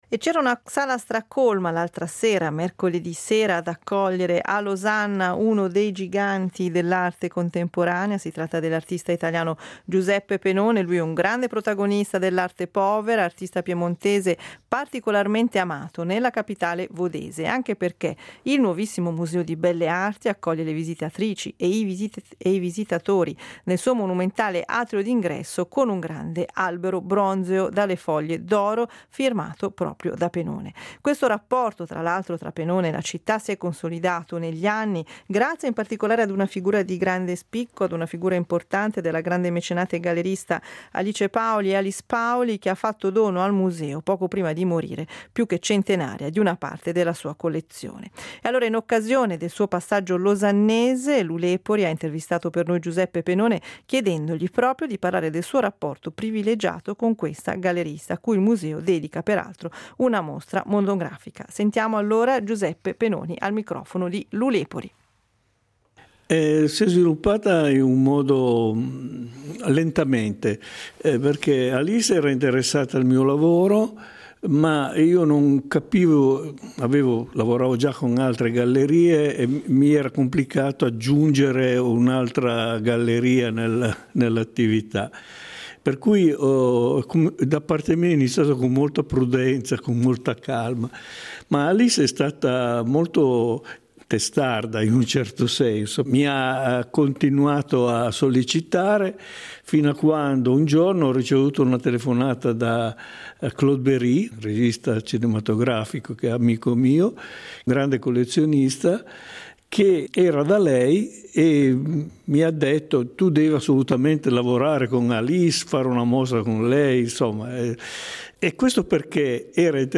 Intervista Giuseppe Penone